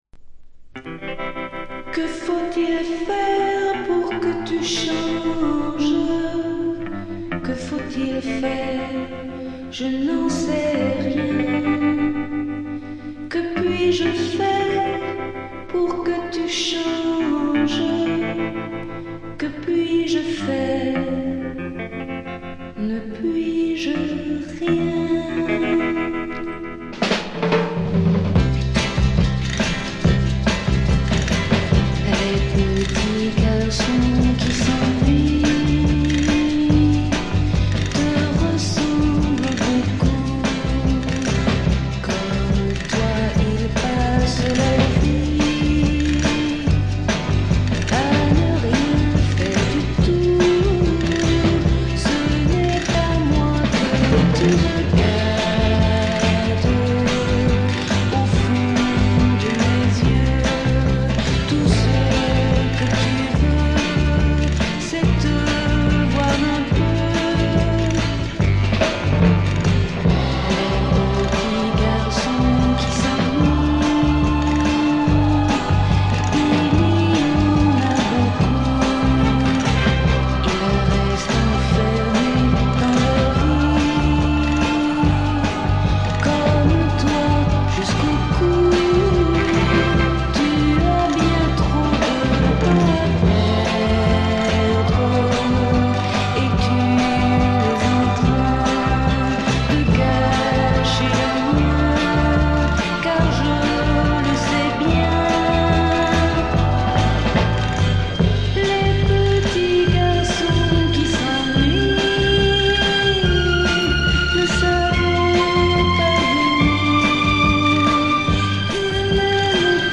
他はほとんどノイズ感なしで良好に鑑賞できます。
試聴曲は現品からの取り込み音源です。